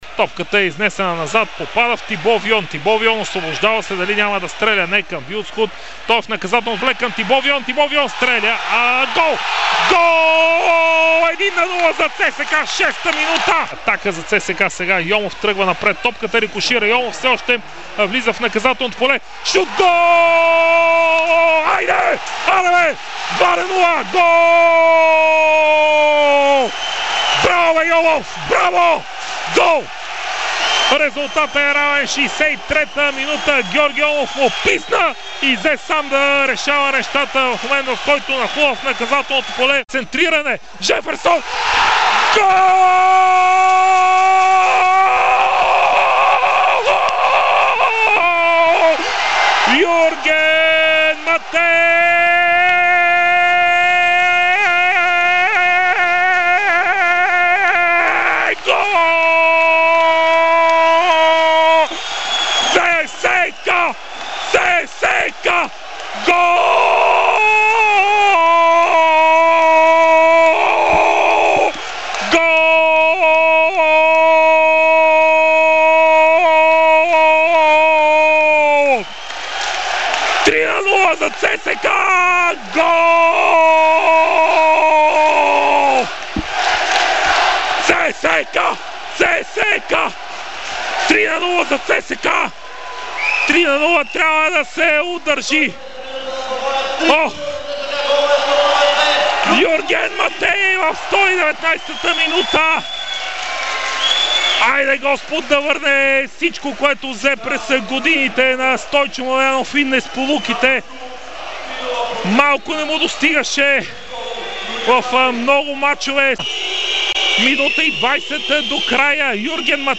предаде на слушателите на Дарик радио по изключително емоционален начин голямата „червена“ победа